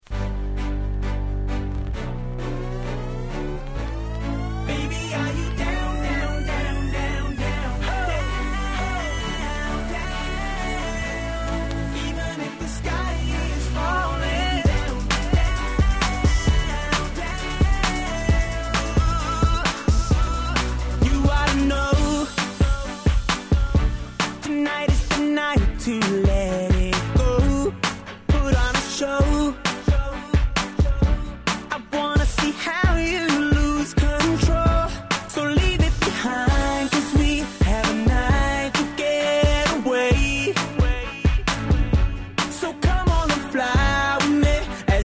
09' Super Hit R&B !!!
この曲がヒットしたくらいの頃から、メインストリームのR&BがBPM早目のクロスオーバーな物に変わってきましたね。
00's キャッチー系